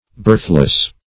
Birthless \Birth"less\, a.